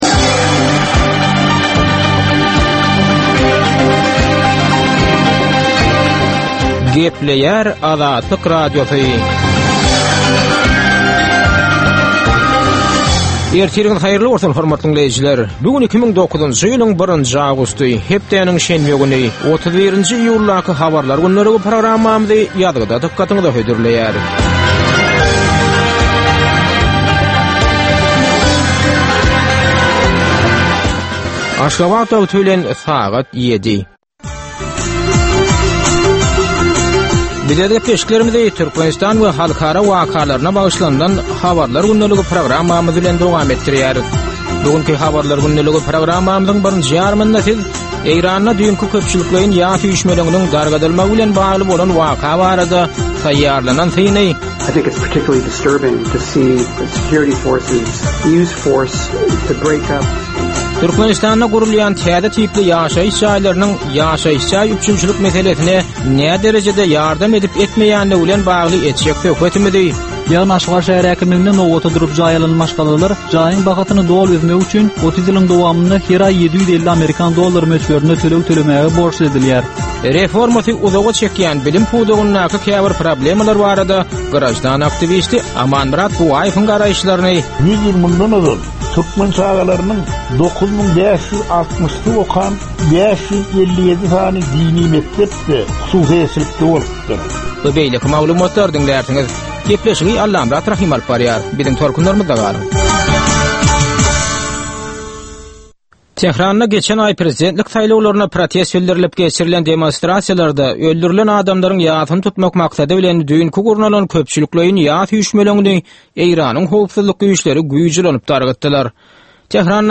Türkmenistandaky we halkara arenasyndaky sonky möhüm wakalar we meseleler barada ýörite informasion-analitiki programma. Bu programmada sonky möhüm wakalar we meseleler barada ginisleýin maglumatlar, analizler, synlar, makalalar, söhbetdeslikler, reportažlar, kommentariýalar we diskussiýalar berilýär.